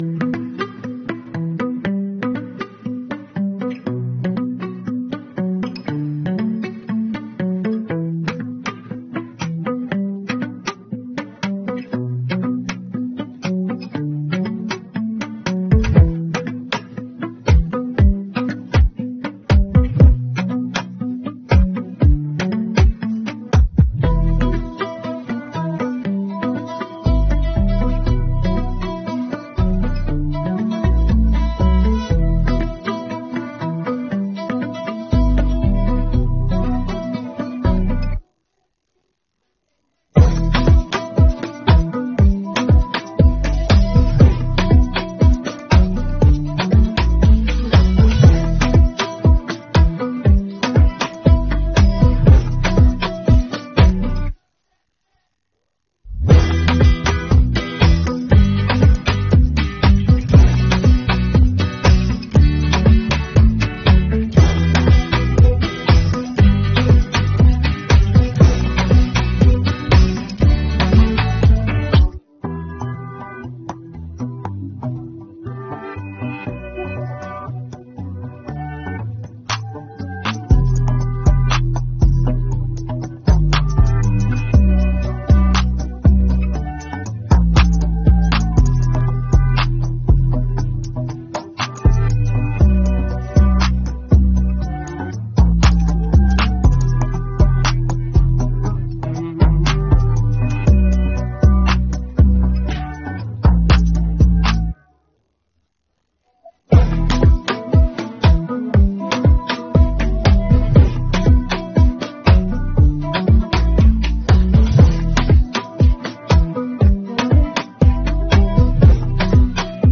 українське караоке